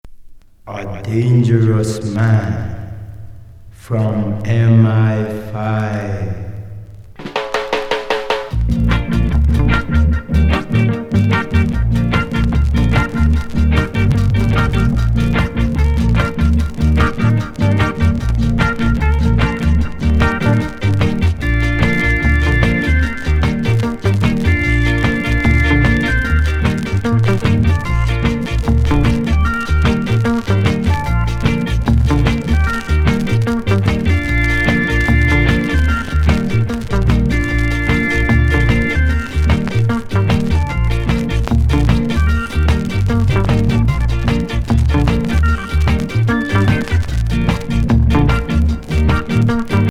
UK MONO！